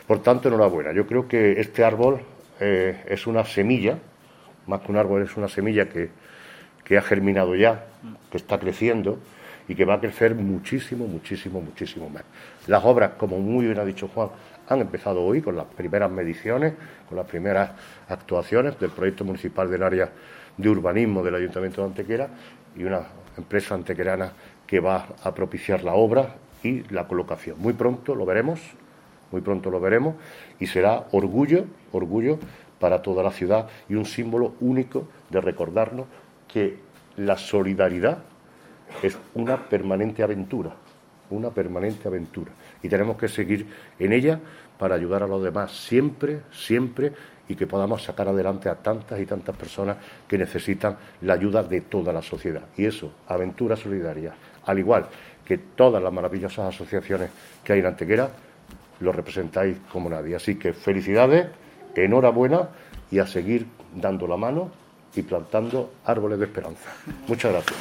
El alcalde de Antequera ha felicitado a Aventura Solidaria por la puesta en marcha de este original e innovador proyecto solidario, haciendo referencia al destacado aspecto solidario que caracteriza a los vecinos de nuestra ciudad: "Antequera es un reflejo de su sociedad, y Antequera es una ciudad profundamente solidaria y colaborativa.
Cortes de voz